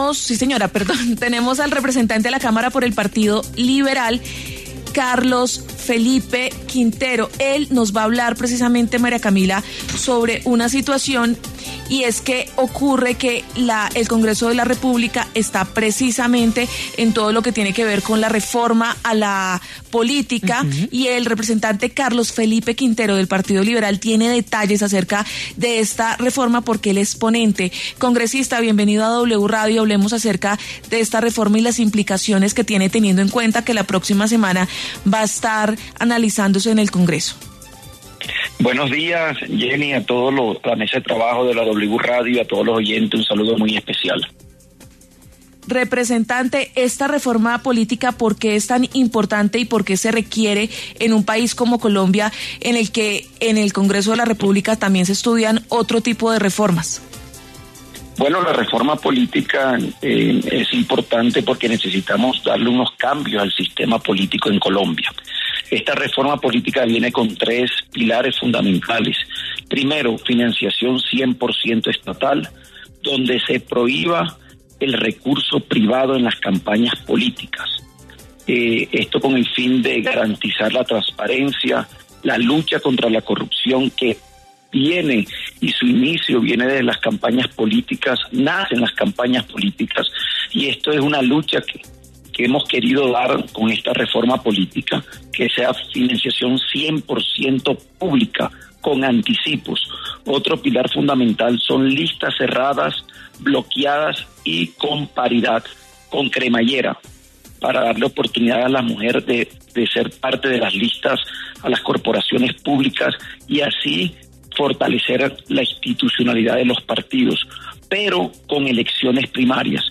El representante de la Cámara por el Partido Liberal, Carlos Felipe Quintero, habló en W Fin de Semana sobre el segundo debate de la reforma política que se dará la próxima semana en la Plenaria de la Cámara.